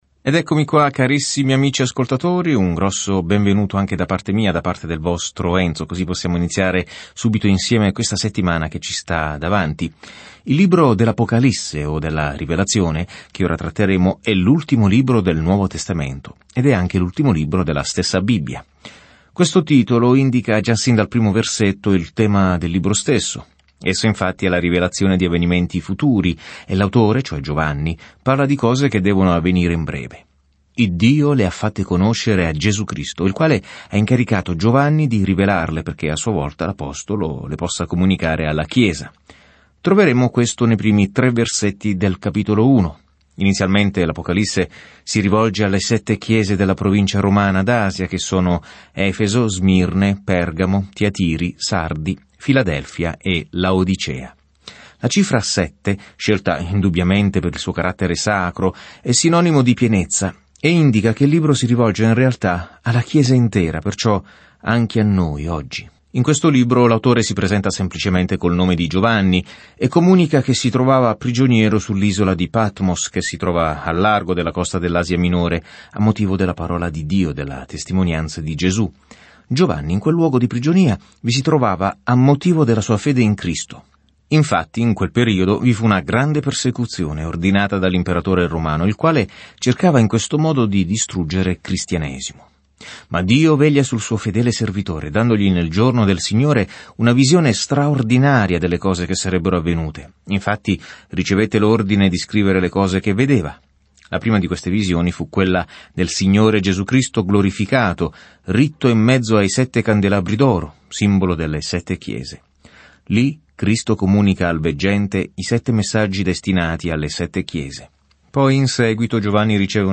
Scrittura Apocalisse di Giovanni 1:1 Inizia questo Piano Giorno 2 Riguardo questo Piano L’Apocalisse registra la fine dell’ampia linea temporale della storia con l’immagine di come il male verrà finalmente affrontato e il Signore Gesù Cristo governerà con ogni autorità, potere, bellezza e gloria. Viaggia ogni giorno attraverso l'Apocalisse mentre ascolti lo studio audio e leggi versetti selezionati della parola di Dio.